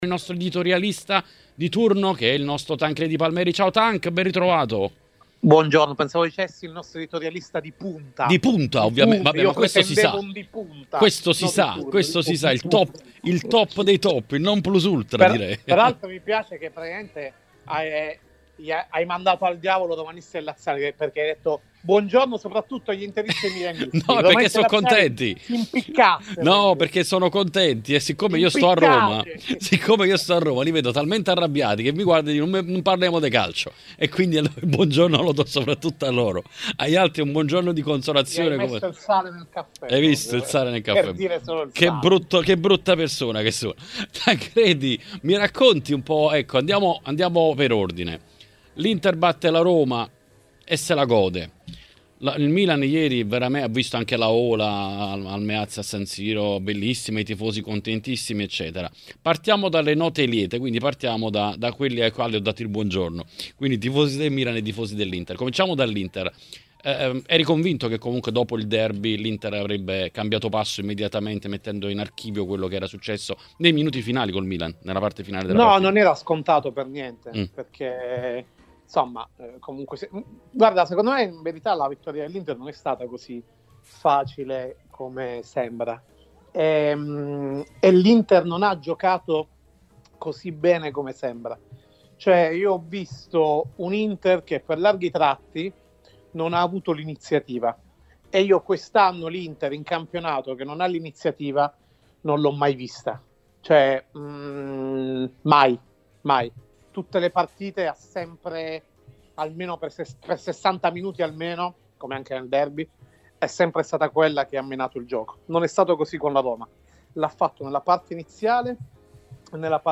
ospite dell’editoriale di TMW Radio.